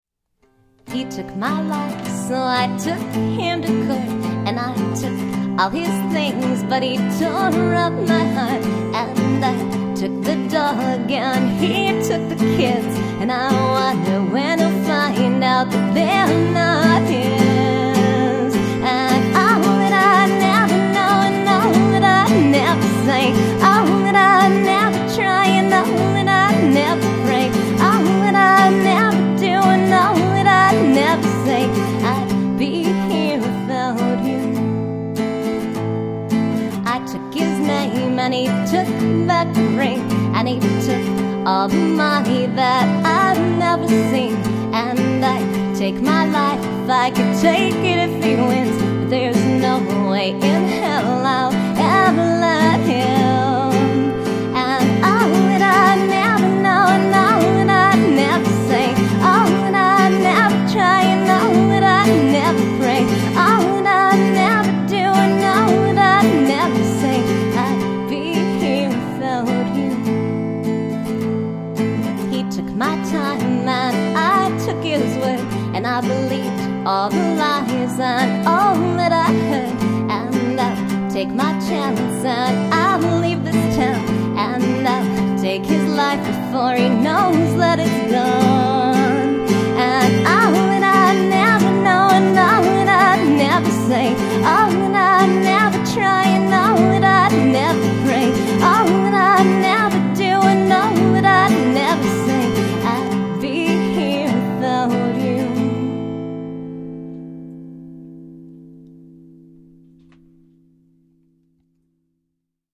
64kbps mono MP3
guitar and vocals